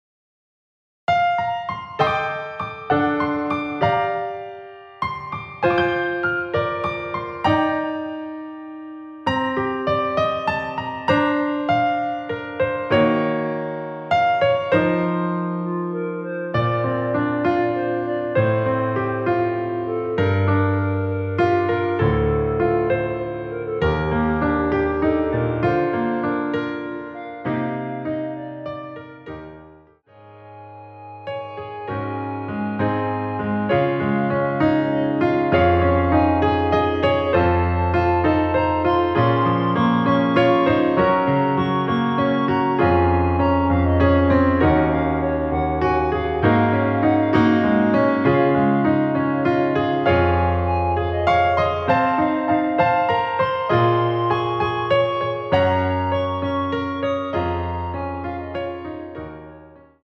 원키에서 (+5)올린 MR입니다.
Bb
노래방에서 노래를 부르실때 노래 부분에 가이드 멜로디가 따라 나와서
앞부분30초, 뒷부분30초씩 편집해서 올려 드리고 있습니다.
중간에 음이 끈어지고 다시 나오는 이유는